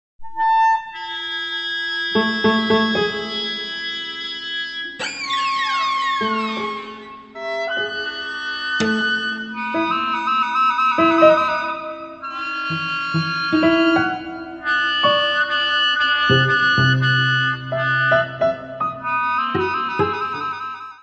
: stereo; 12 cm + folheto
Music Category/Genre:  New Musical Tendencies